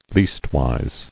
[least·wise]